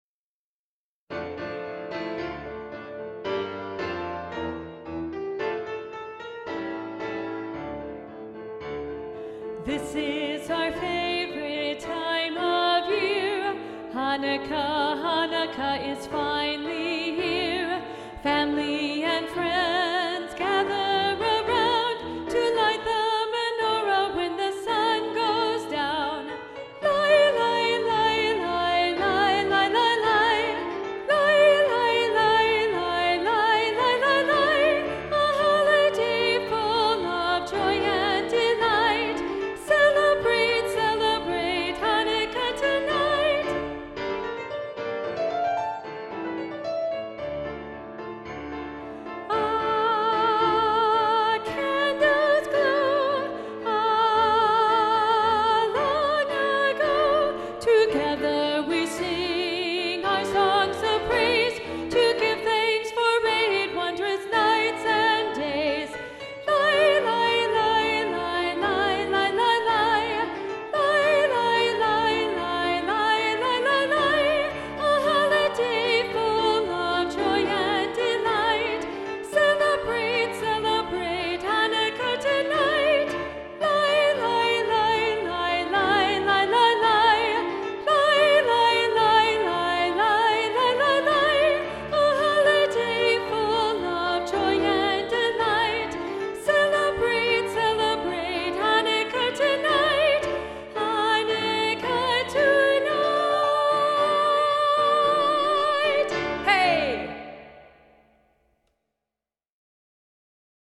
2-Part – Part 2 Muted